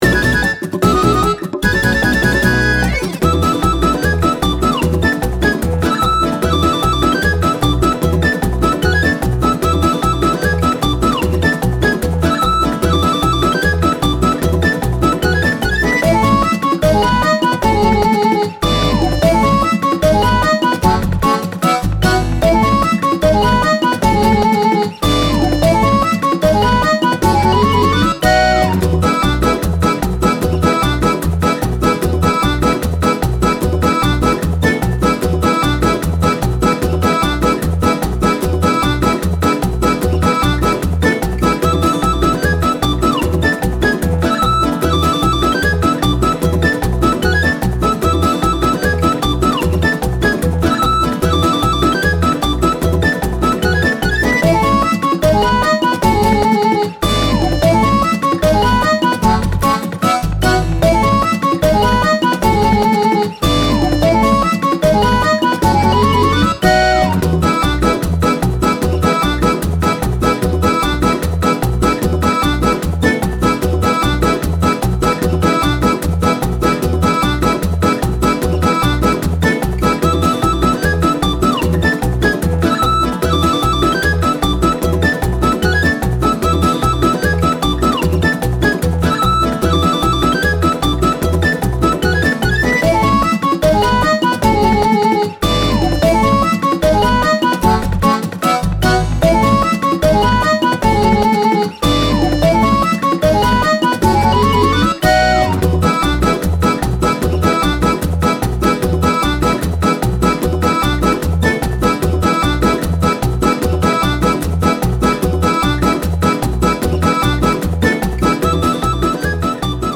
忙しく慌ただしいコミカルな場面によく合うBGMです。
BPM 150
慌ただしい アコースティックギター
コミカル 明るい 忙しい ギャグ 楽しい 盛り上がる
ポップ ベース